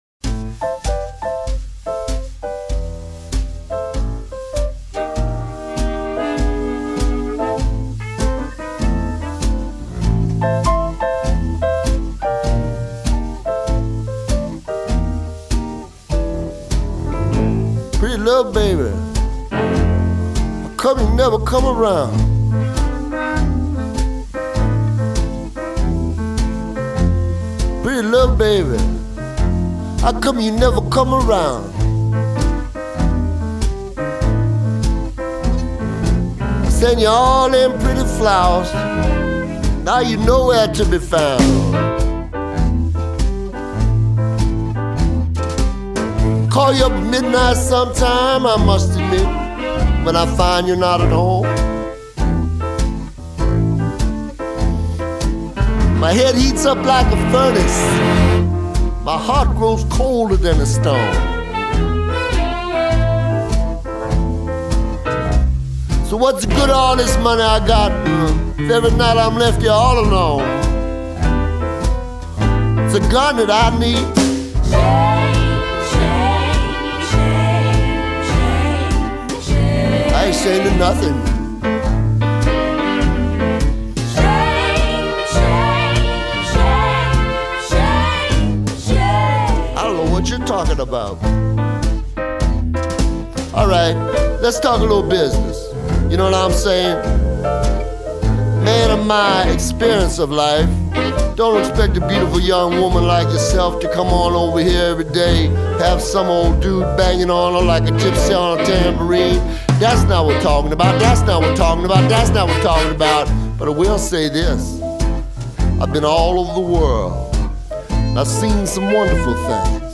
It’s all about the delivery.